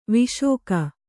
♪ viśoka